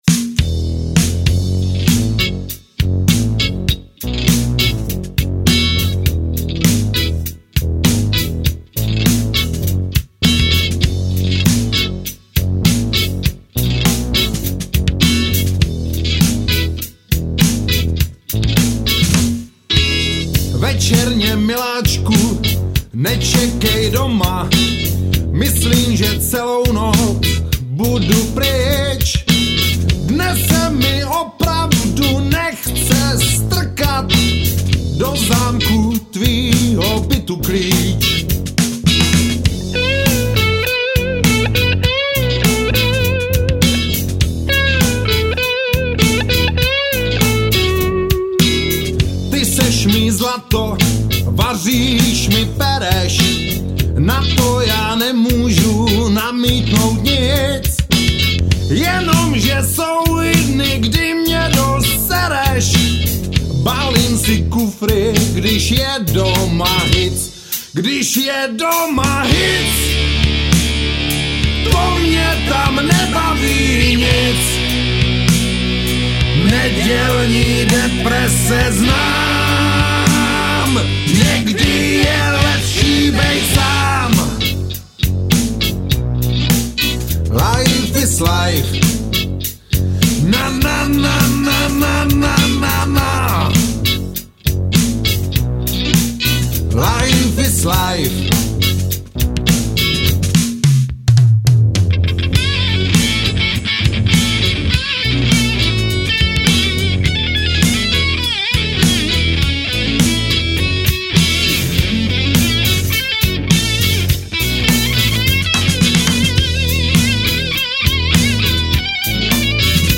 Ten "reggae" rytmus přinesl už neznámo kdo